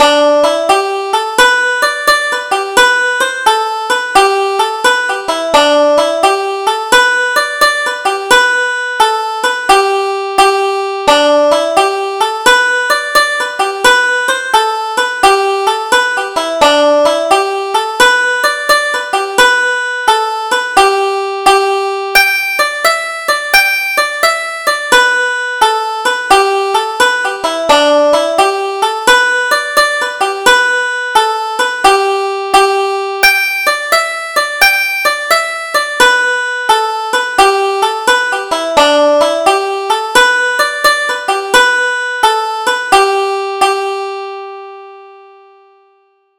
Double Jig: Sergt. Cahill's Favorite